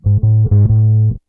BASS 19.wav